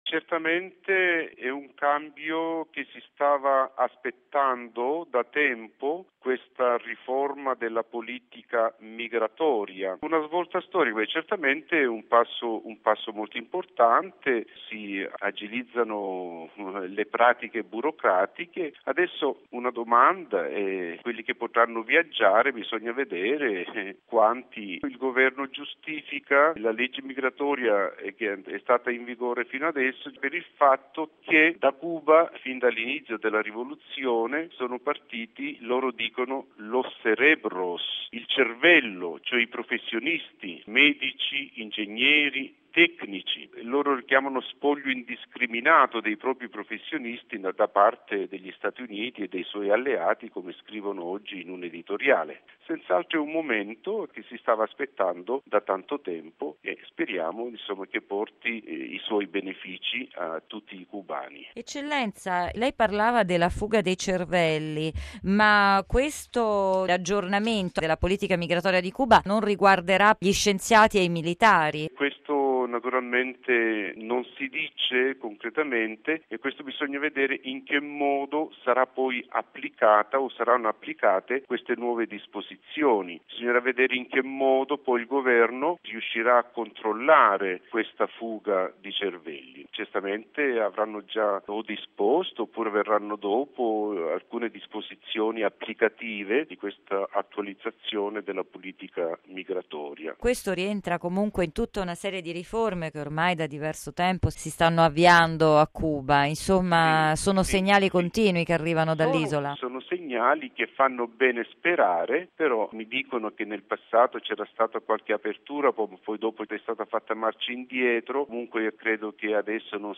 Cuba: "cancellati" i permessi d'uscita dal Paese, basta il passaporto. Intervista col nunzio